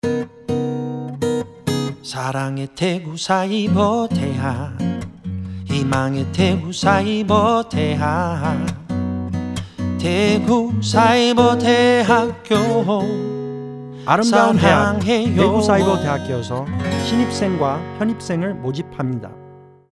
2014학년도 입시모집 라디오 듣기 다운로드
RadioCM_2014.mp3